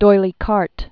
(doilē kärt), Richard